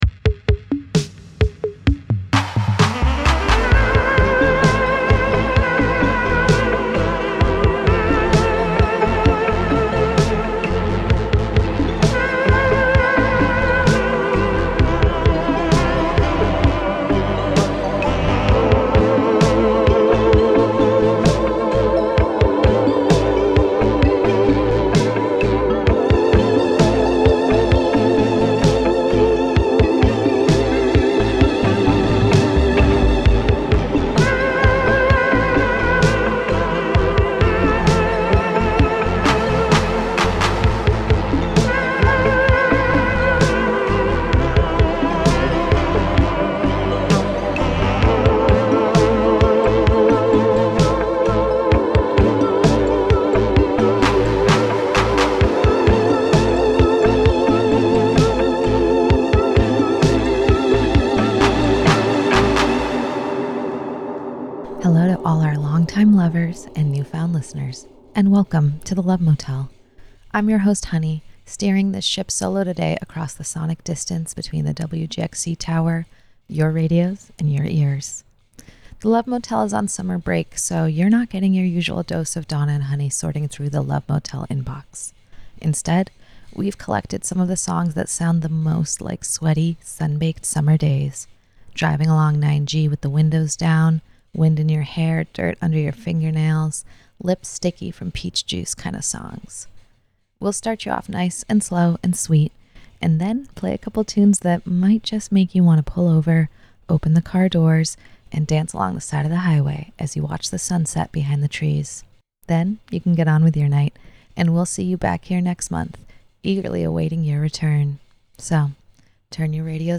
The Love Motel is a monthly radio romance talk show with love songs, relationship advice, and personals for all the lovers in the upper Hudson Valley.